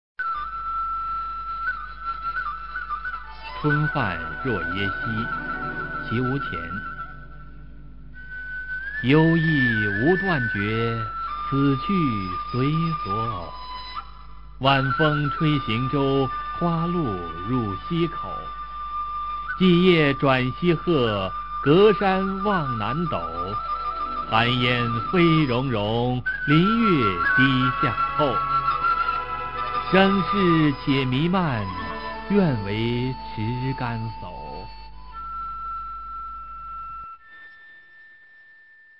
[隋唐诗词诵读]綦毋潜-春泛若耶溪a 配乐诗朗诵